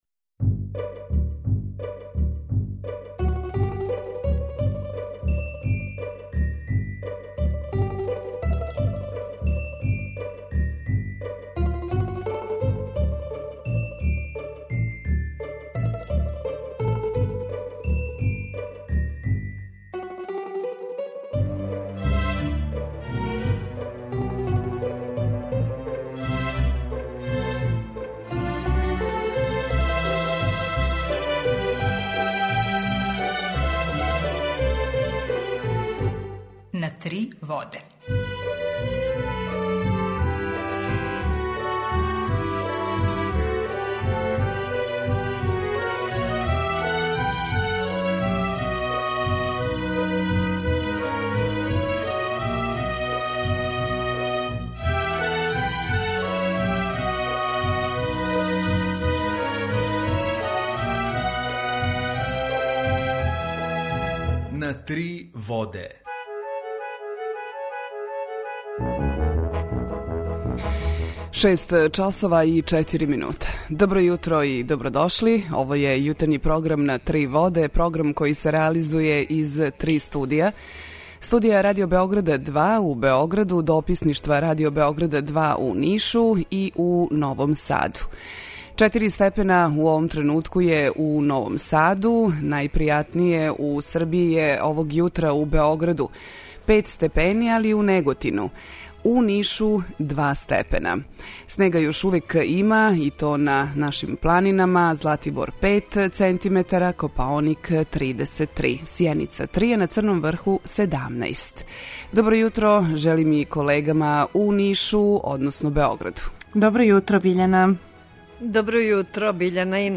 У једној емисији из четири различита студија, градоначелници Београда, Новог Сада, Ниша и Бањалуке - Зоран Радојичић, Милош Вучевић, Дарко Булатовић и Игор Радојичић разговараће о актуелним темама које су обележиле годину која је иза нас и плановима за годину која долази. У програму ће бити речи о свакодневним проблемима који тиште наше суграђане - комуналним, саобраћајним, привредним, инфраструктурним. Градоначелници ће говорити о плановима за развој ових градова и пројектима.